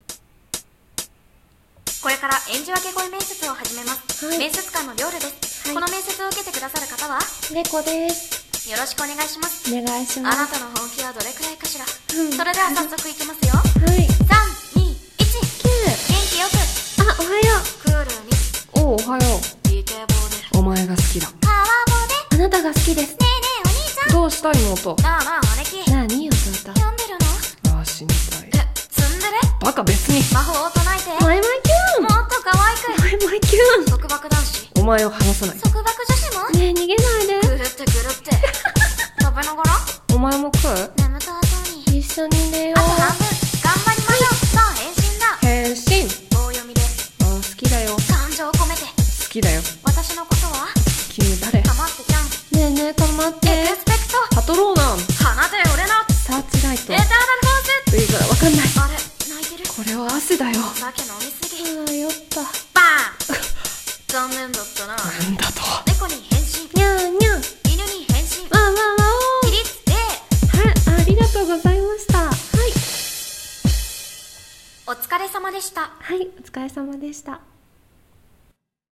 【声面接】演じ分け声面接